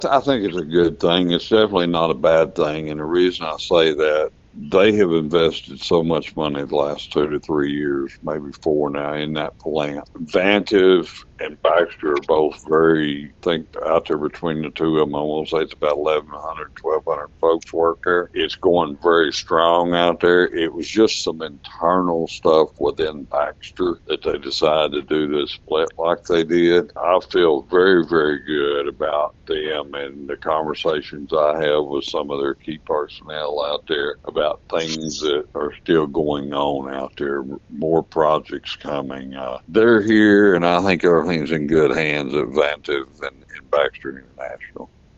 Following the event, Mayor Hillrey Adams shared key takeaways in an interview with KTLO, Classic Hits and The Boot News.